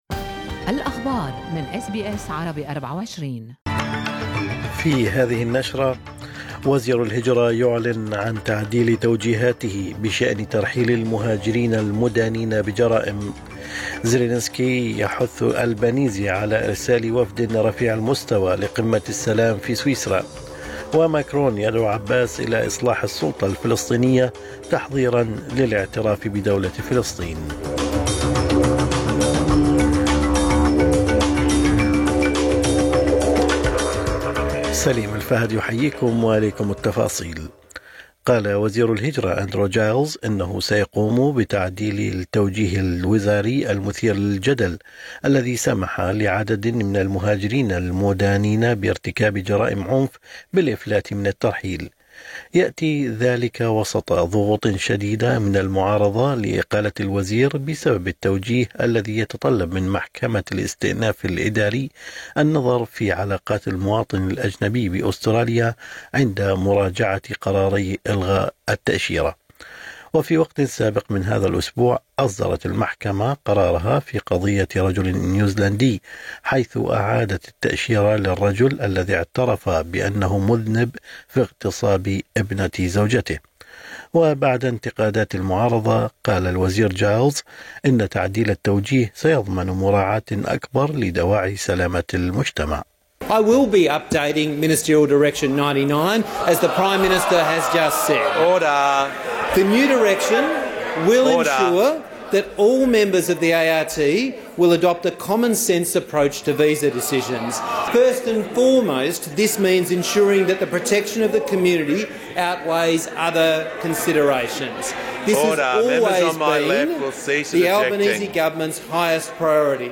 نشرة أخبار الصباح 30/5/2024